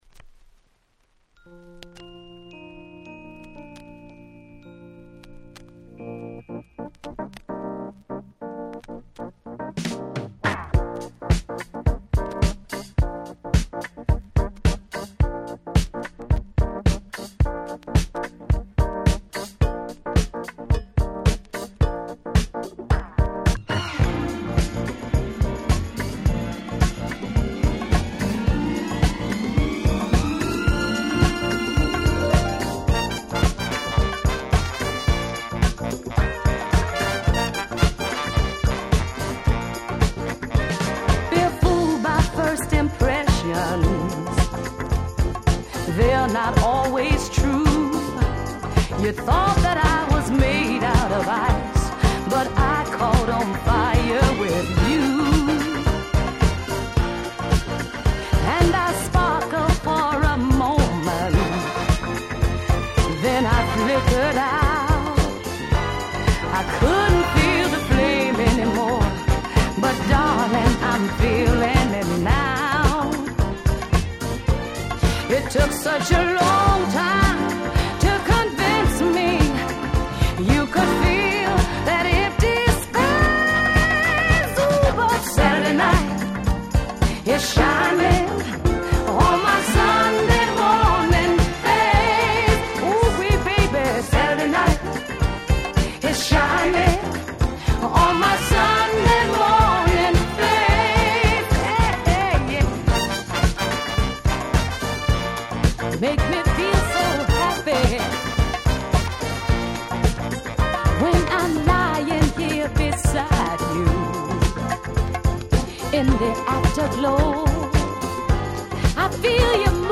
※試聴ファイルは別の盤から録音してございます。
79' Dance Classic超名盤！！
Disco